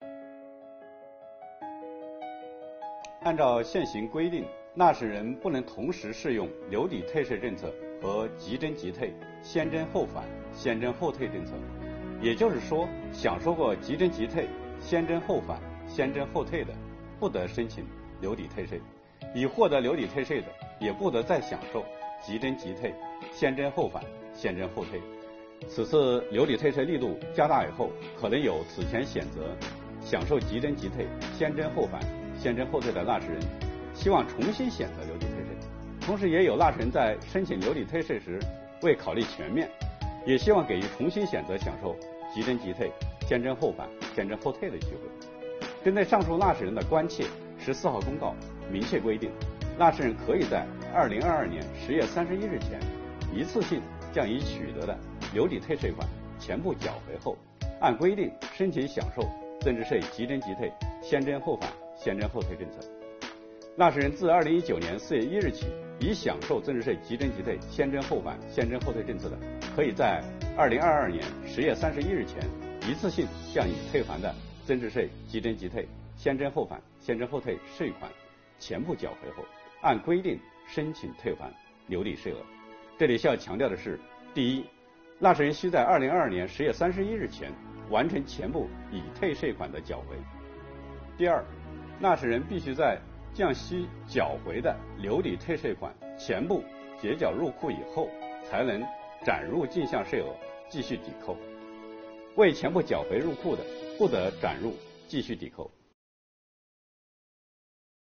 日前，新一期“税务讲堂”第五讲开讲。国家税务总局货物和劳务税司副司长刘运毛担任主讲人，详细解读了有关2022年大规模留抵退税政策的重点内容以及纳税人关心的热点问题。